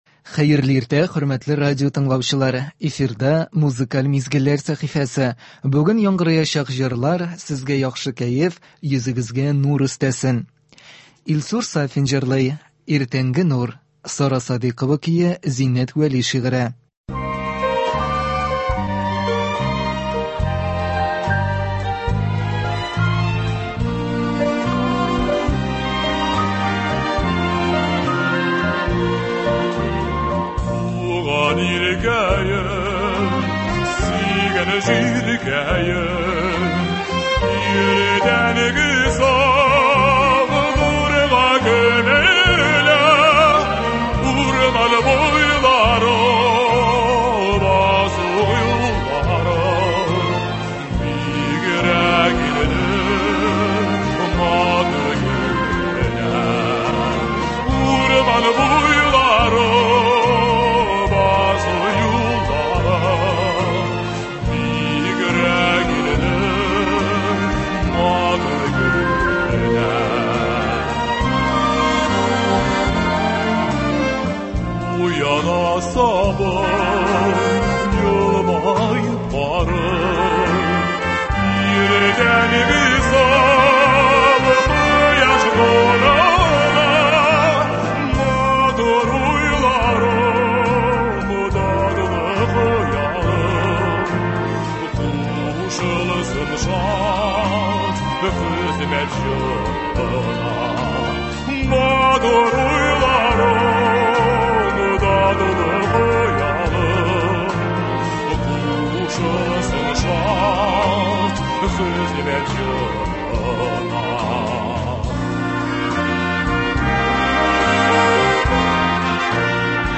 Бүгенге салкынча көзге иртәне моңлы җырларыбыз белән каршылыйбыз.